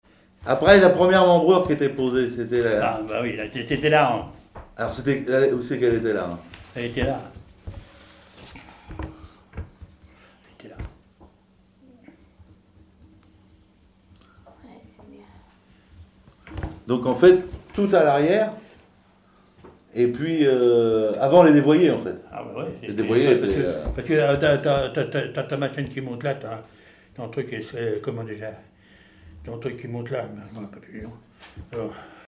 Témoignages d'un charpentier naval sur les techniques locales
Catégorie Témoignage